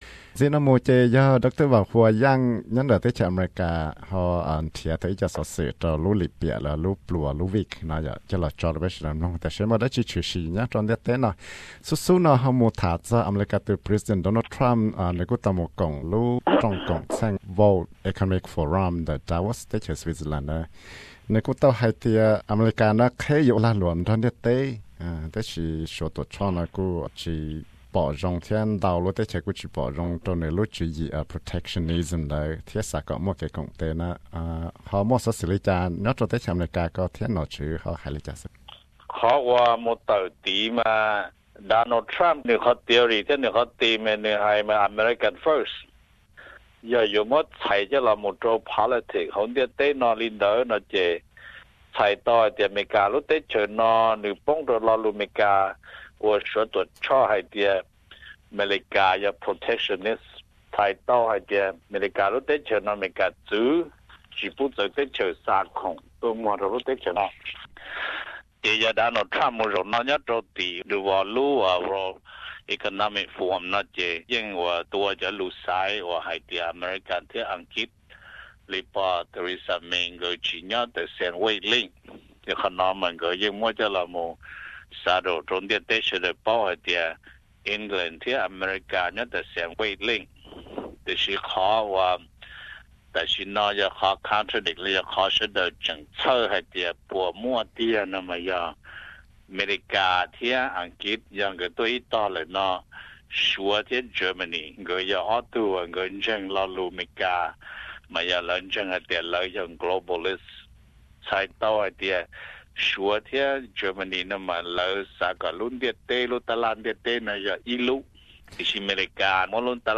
Stringer report: World's trade, Turkey 's conflcits